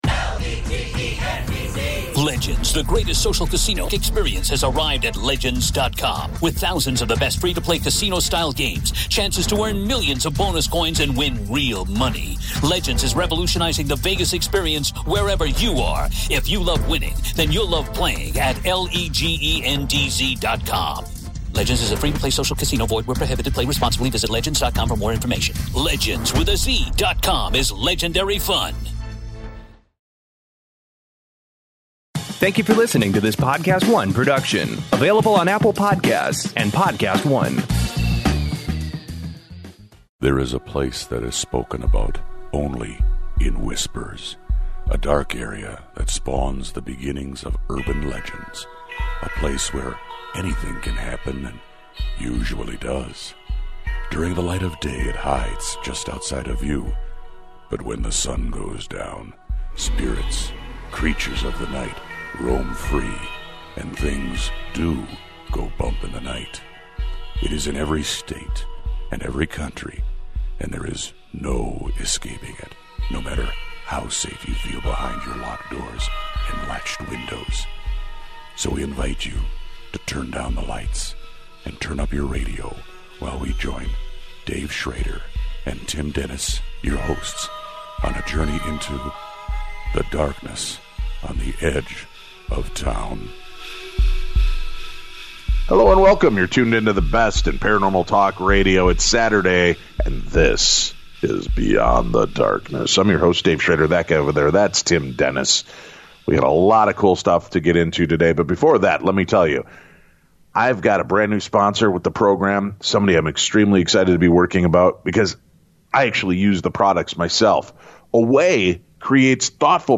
All that and more today on the Best in Paranormal Talk Radio.